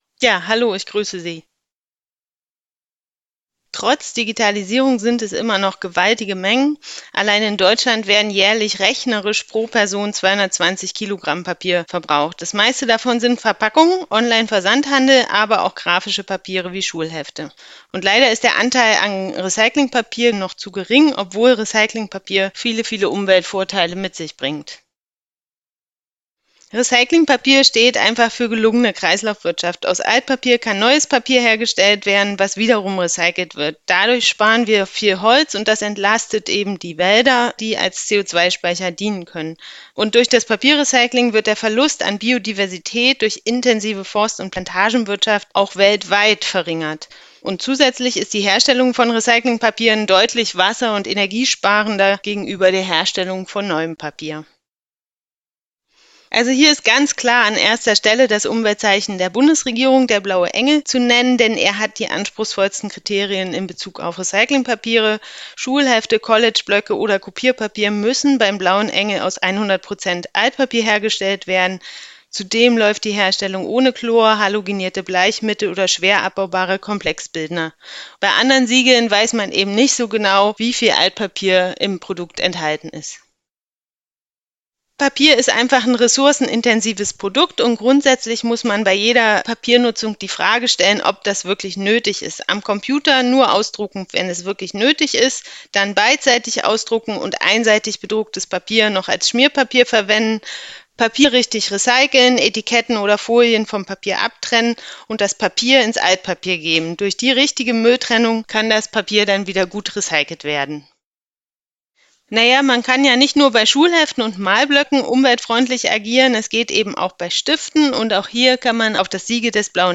O-TON-PAKET / 02:20 Min.
O-Töne: Nachhaltiger Schulstart!